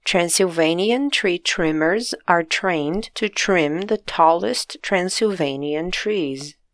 Mas não se preocupe: os áudios para que você escute as pronúncias corretas e pratique todos eles estão presentes para te ajudar, como sempre!
Confira (e pratique os sons de “t”, claro)!